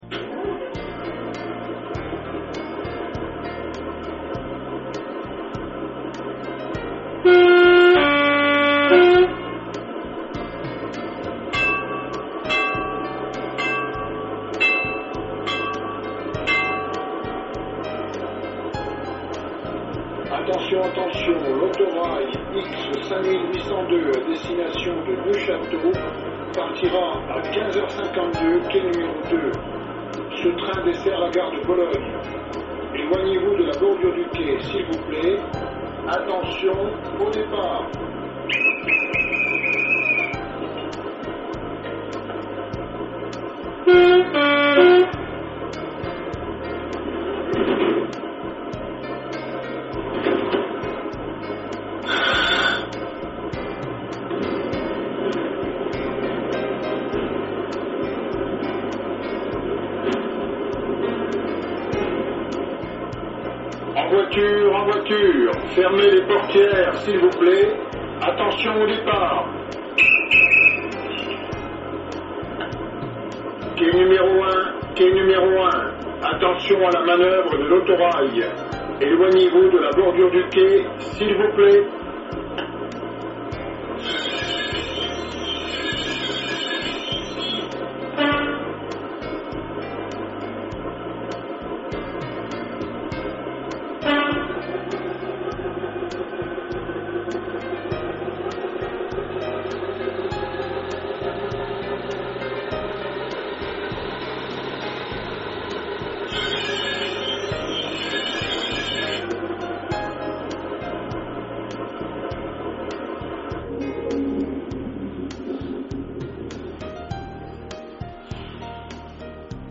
Der eMOTION XLS Sounddecoder gibt die authentischen Geräusche einer Lokomotive in hochwertiger digitaler Qualität wieder. Hierzu werden Soundaufnahmen direkt am Vorbild vorgenommen und dann im Soundlabor für die Elektronik abgeglichen.
Die Hintergrundmusik in den MP3-Demo Dateien ist nicht im XLS-Modul vorhanden!
Soundgeräusch